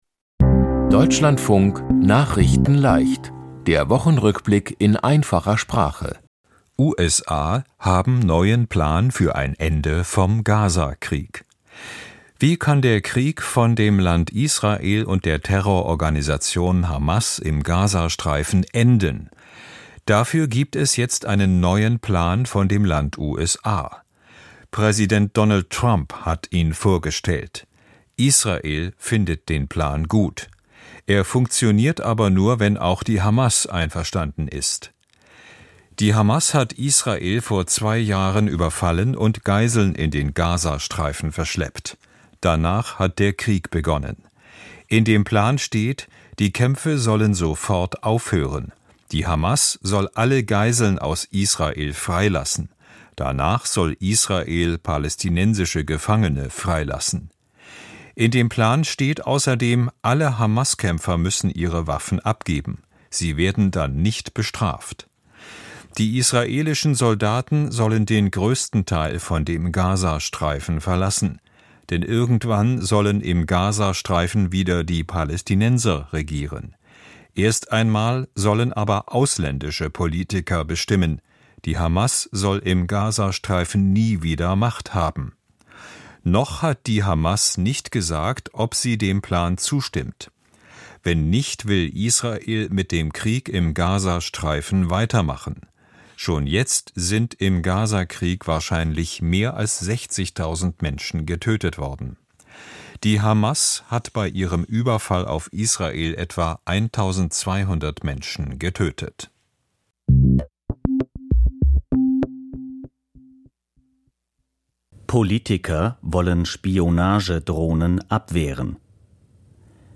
Die Themen diese Woche: USA haben neuen Plan für ein Ende vom Gaza-Krieg, Politiker wollen Spionage-Drohnen abwehren, 35 Jahre Deutsche Einheit, Viele Fische in der Ost-See gestorben und Medaillen für Deutschland bei Para-WM. nachrichtenleicht - der Wochenrückblick in einfacher Sprache.